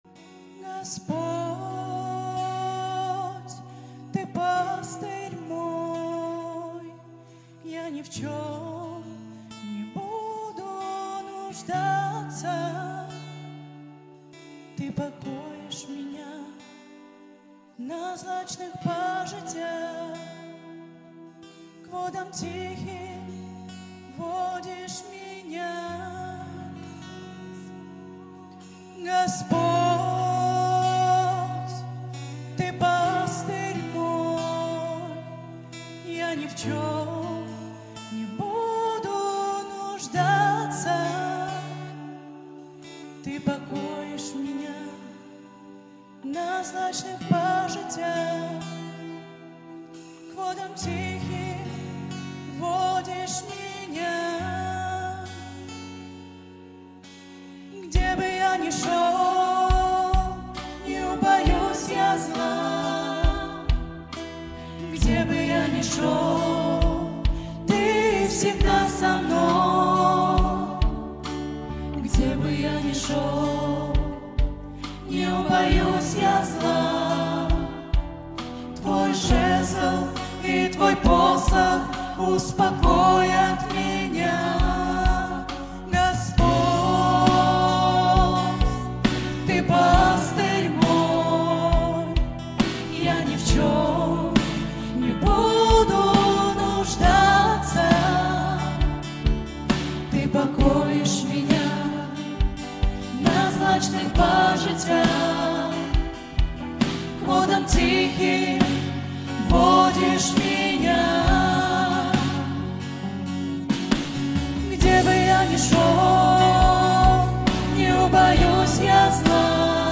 776 просмотров 626 прослушиваний 45 скачиваний BPM: 130